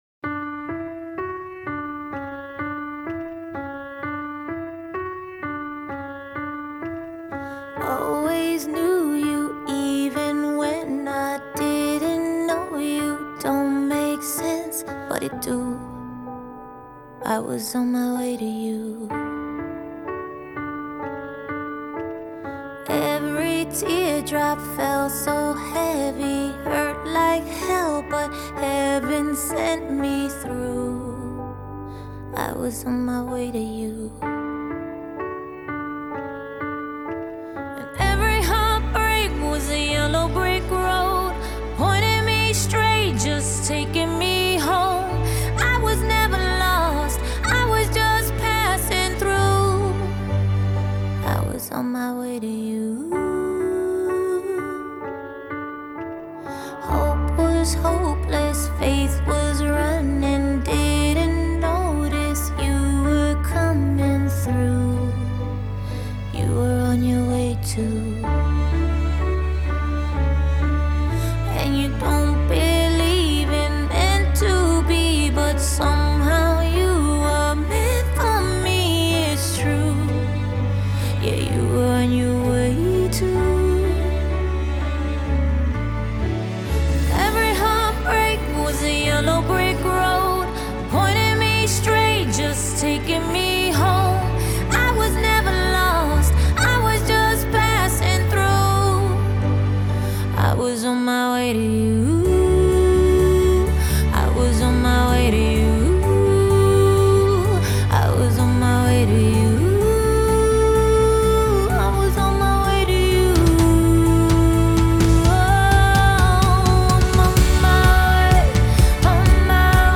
mid-tempo love song